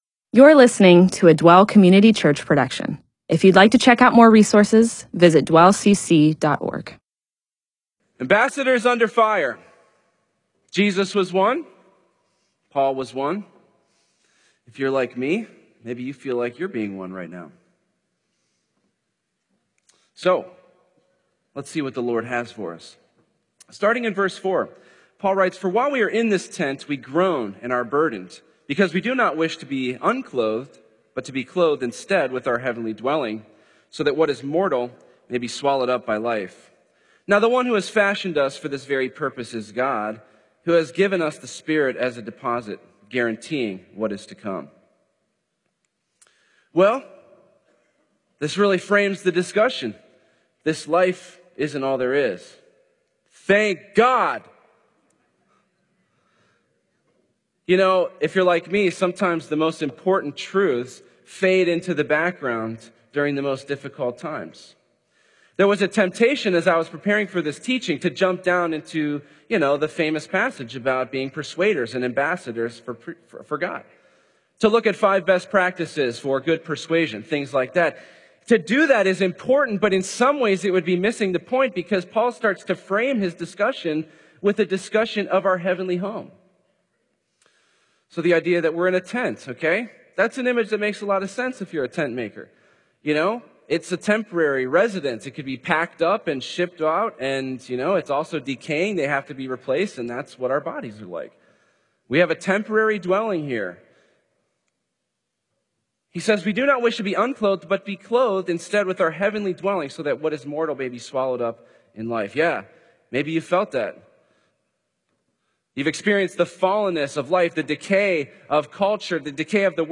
MP4/M4A audio recording of a Bible teaching/sermon/presentation about 2 Corinthians 5:4-20.